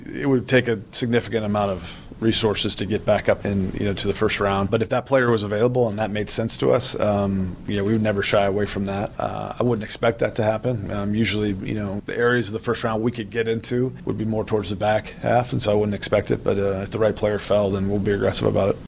Gutekunst talks at NFL Scouting Combine: Packers GM Brian Gutekunst met with the media yesterday at the NFL Scouting Combine.